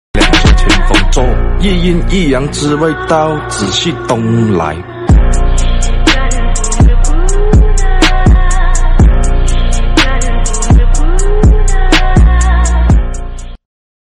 Sepatu hak tinggi ciwi ciwi sound effects free download